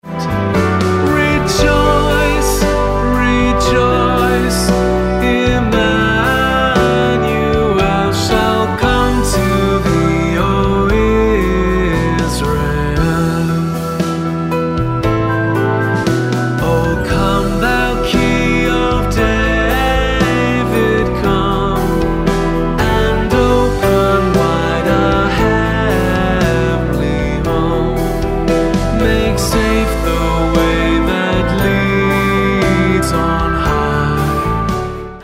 Em